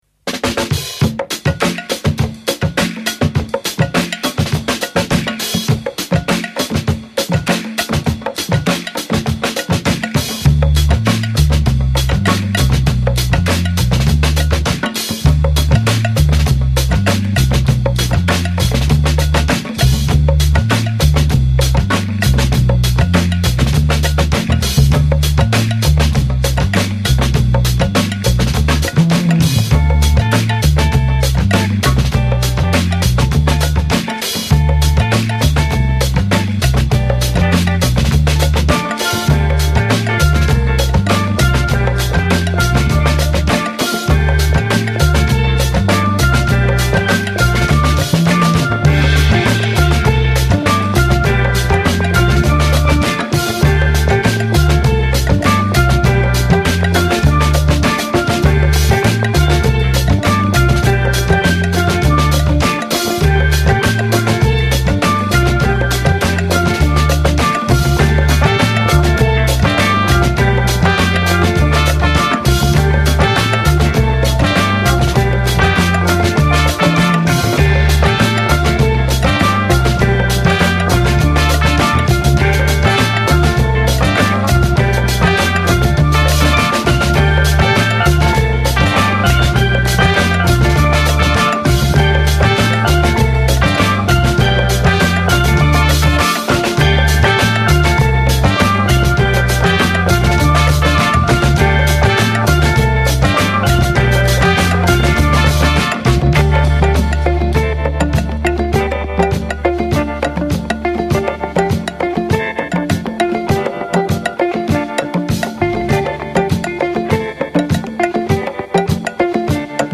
Brilliant cover versions